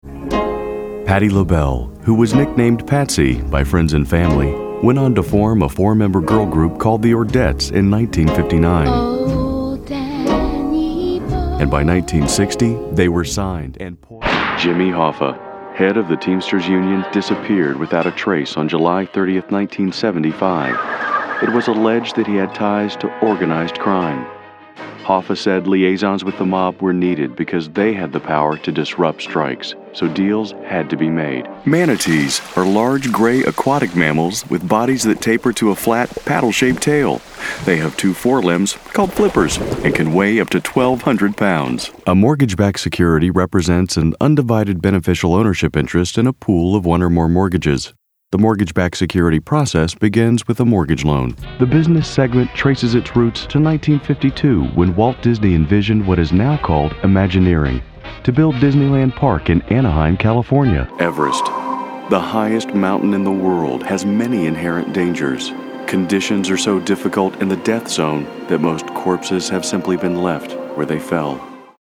mid-atlantic
Sprechprobe: eLearning (Muttersprache):